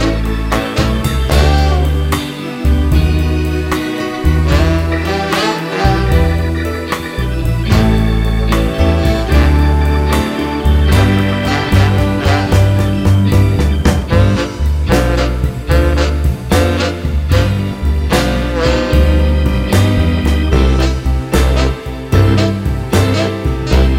no Backing Vocals Jazz / Swing 3:05 Buy £1.50